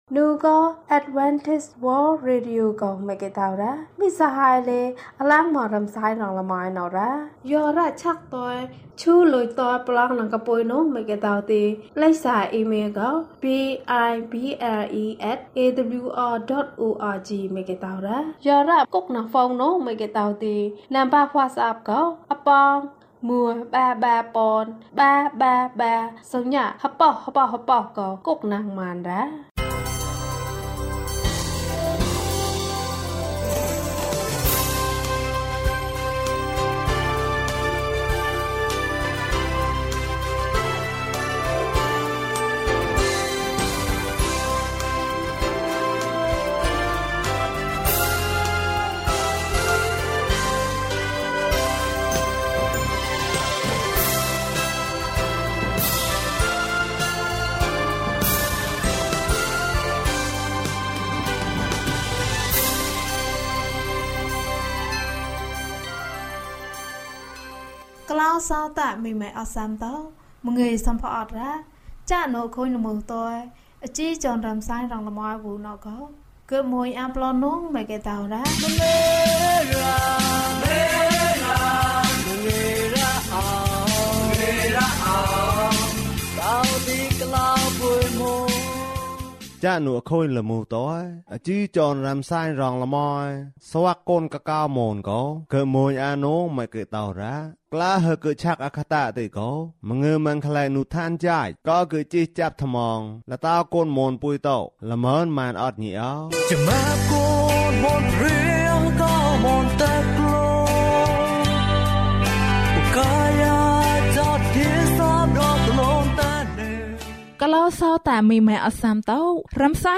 ဓမ္မသီချင်း။တရားဒေသနာ။Adventist World Radio